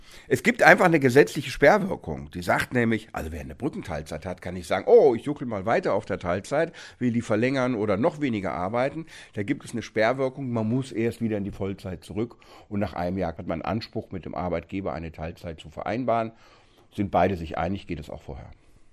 O-Ton: Kein Anspruch auf unbefristete Teilzeit während Brückenteilzeit – Vorabs Medienproduktion